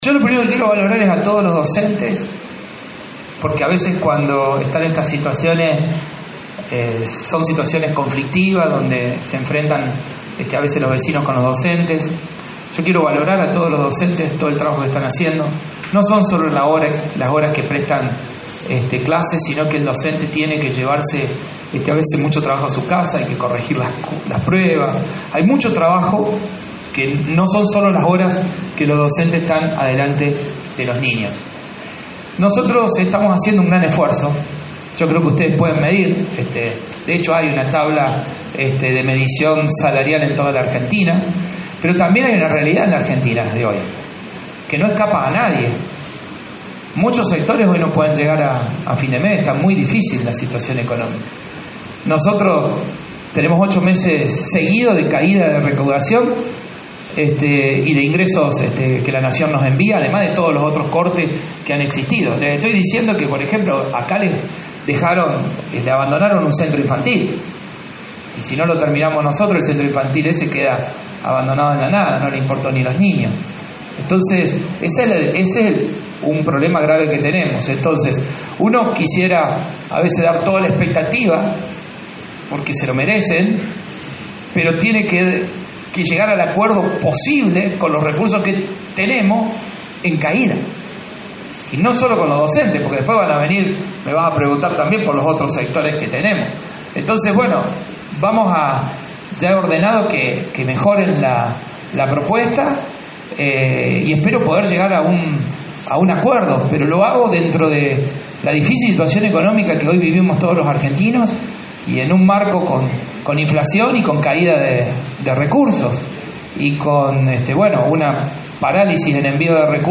El mandatario provincial encabezó una conferencia de prensa en la que se refirió al conflicto docente por el reclamo salarial.